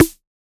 Index of /musicradar/retro-drum-machine-samples/Drums Hits/Tape Path B
RDM_TapeB_MT40-Snr02.wav